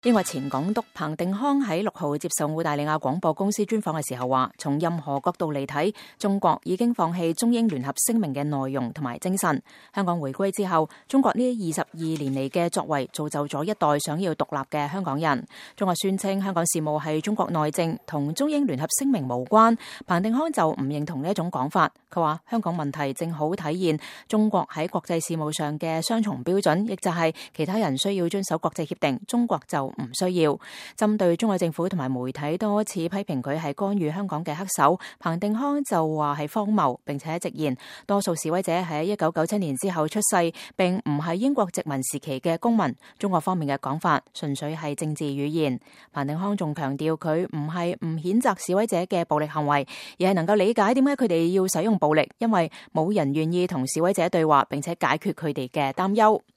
英國末代港督彭定康(Chris Patten)6日接受澳大利亞廣播公司(ABC)專訪時表示，從任何角度來看，中國已經放棄《中英聯合聲明》的內容及精神，香港回歸以後，中國這22年來的作為，造就了一代想要獨立的香港人。